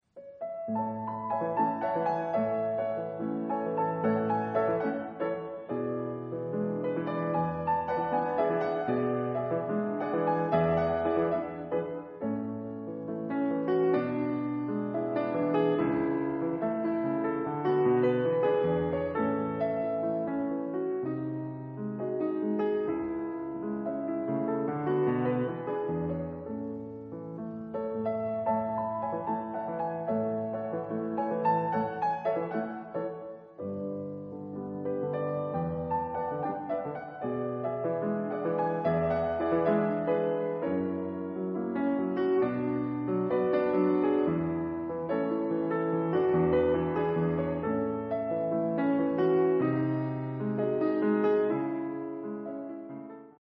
No.14 : Los Enamorados (Tango-Habanera)